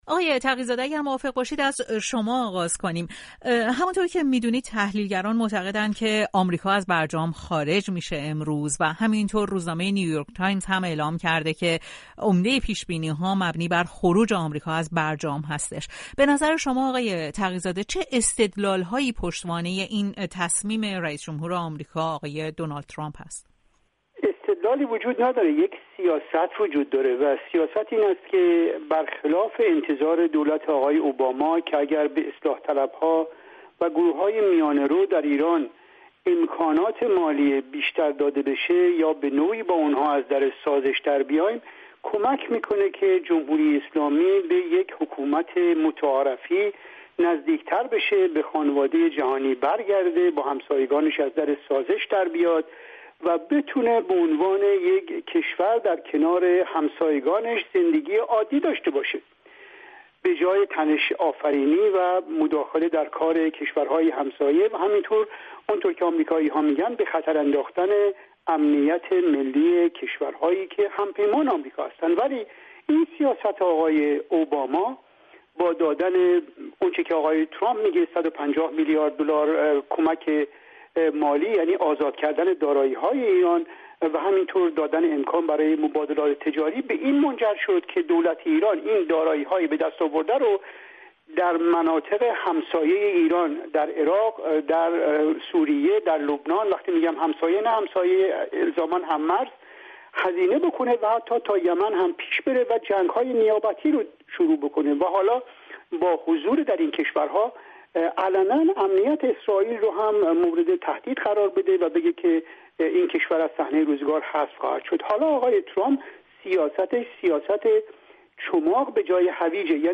مناظره